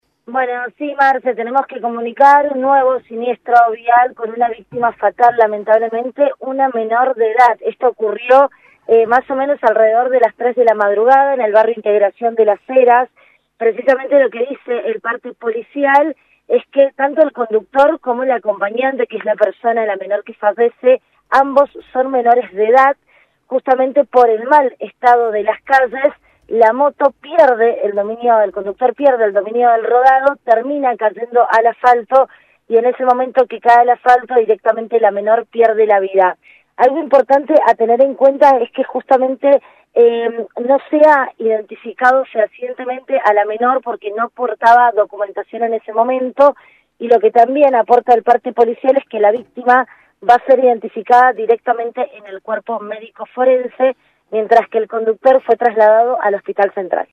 LVDiez - Radio de Cuyo - Móvil de LVDiez- Menor falleció en accidente de moto en el Barrio Integración de Las Heras